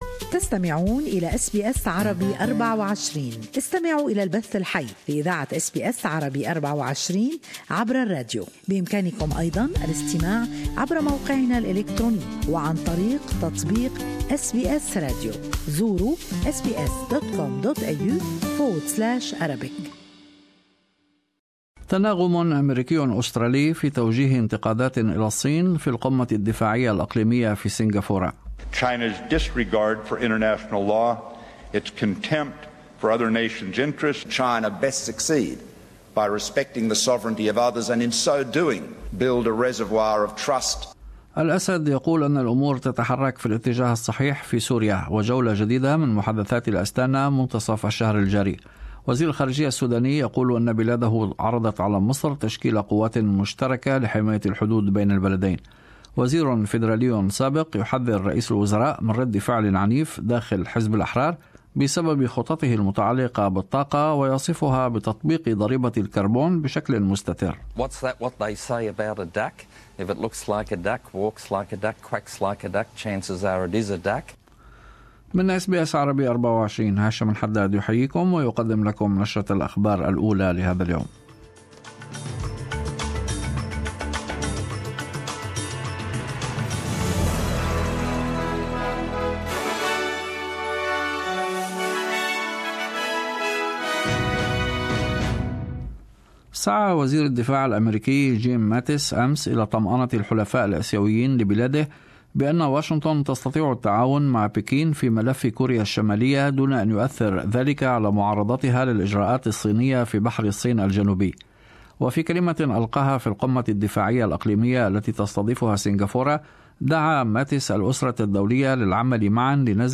Morning news bulletin